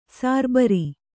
♪ sārbari